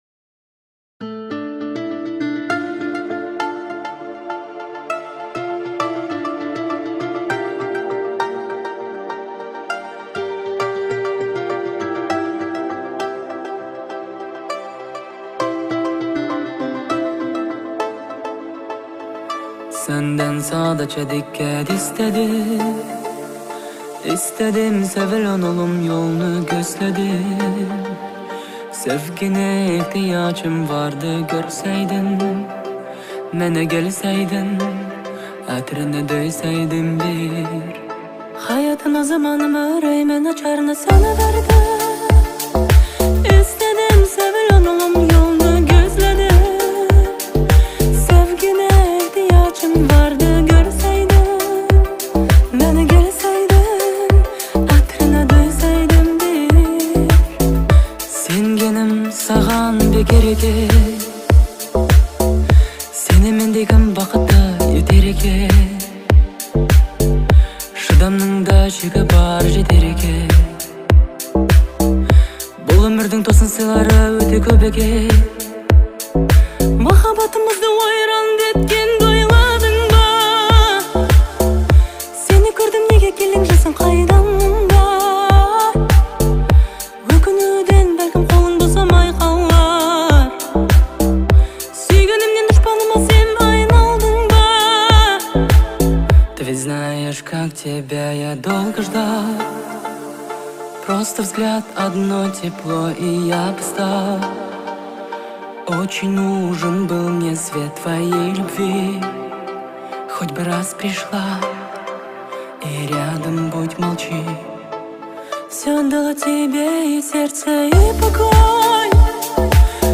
(Двумя Голосами)